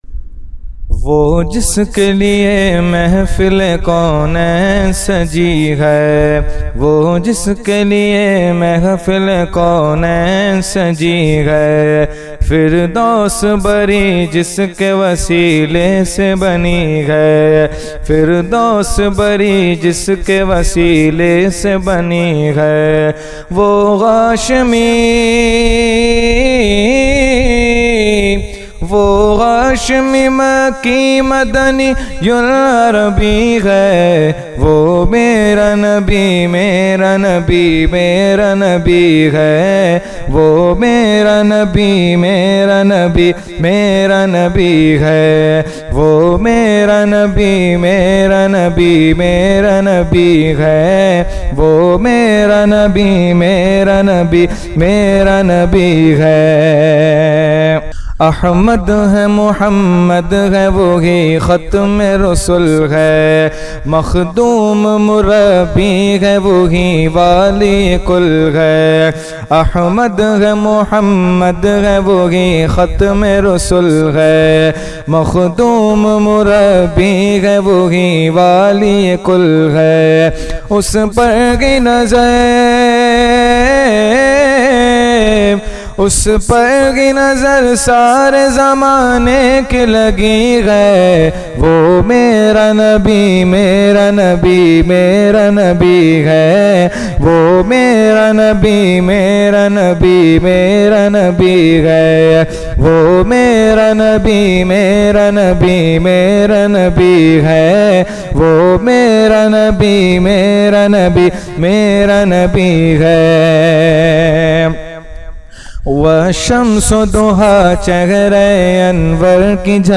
Category : Naat | Language : UrduEvent : Shab e Meraj 2020